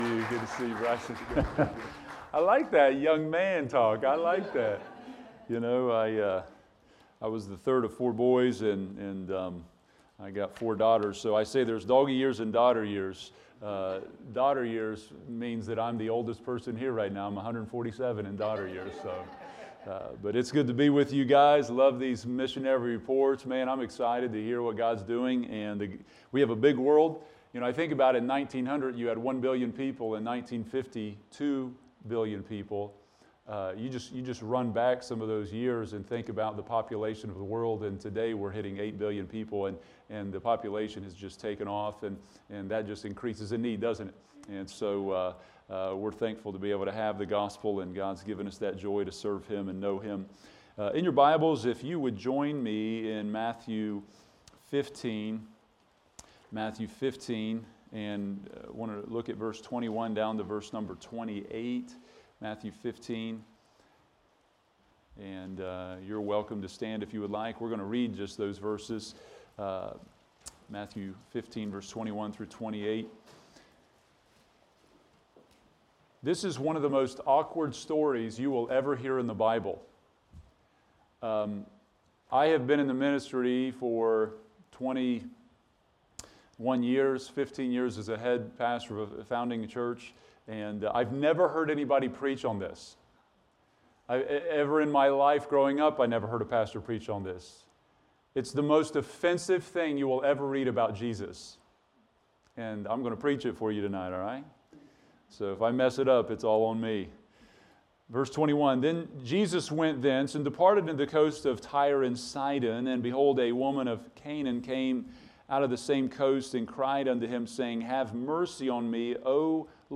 Evening Worship Service